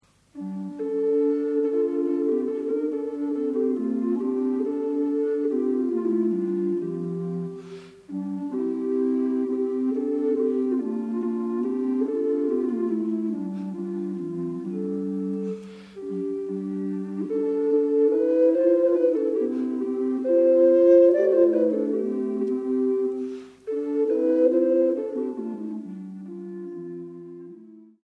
Musique des XII, XIII, XIV° s.
Tous les instruments.